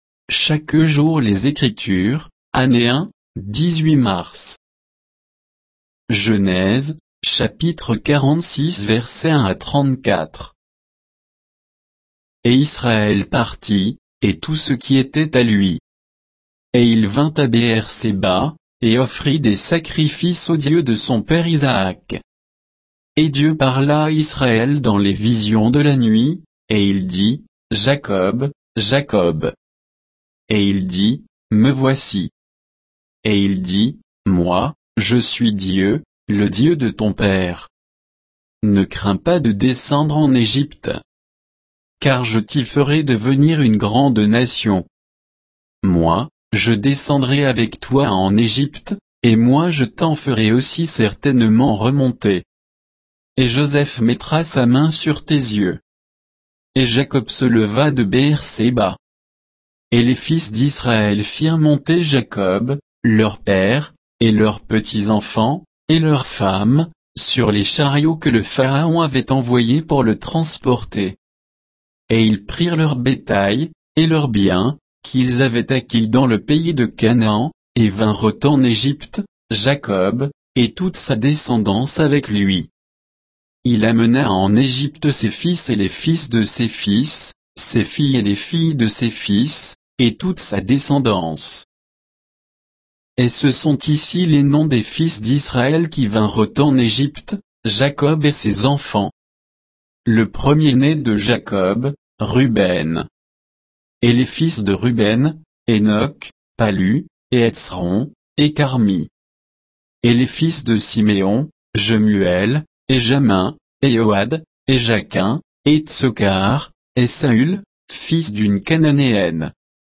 Méditation quoditienne de Chaque jour les Écritures sur Genèse 46